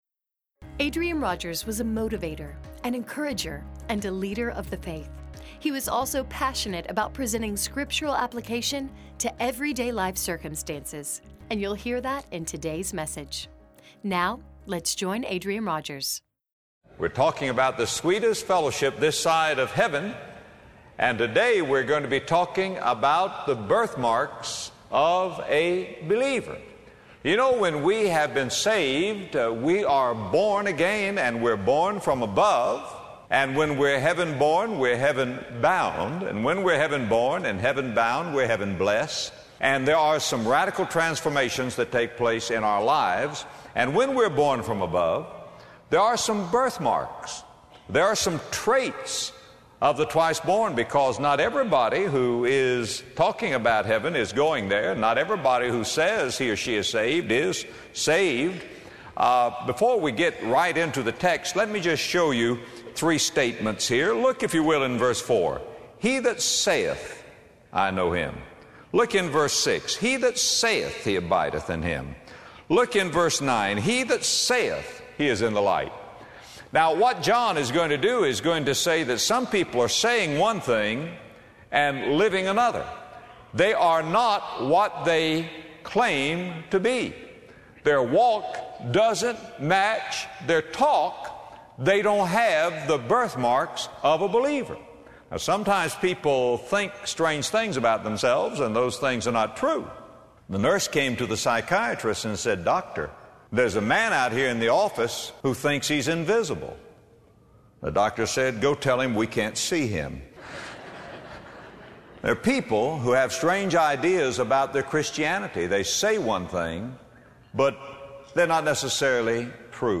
When we got saved, God put some indelible marks upon us. 1 John 2:3-10 reveals three traits of those who are born again. In this message, Adrian Rogers reveals the birthmarks of the believer.